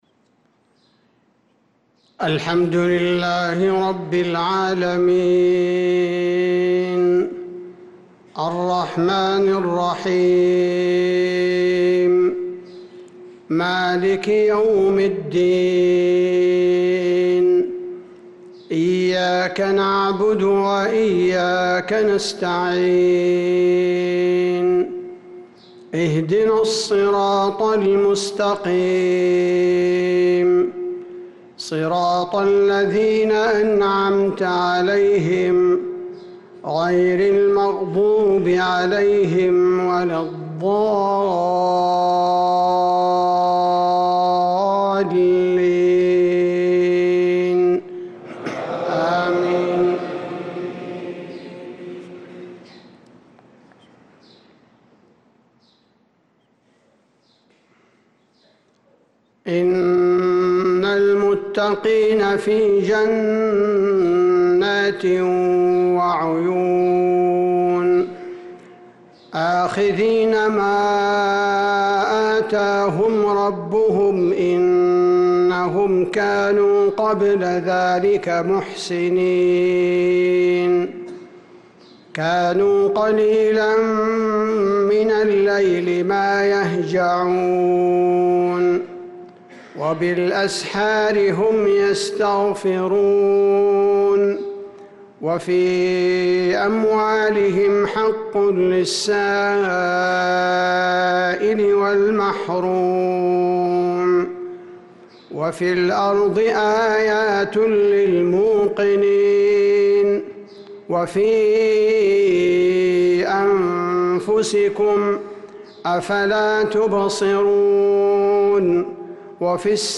صلاة المغرب للقارئ عبدالباري الثبيتي 23 شوال 1445 هـ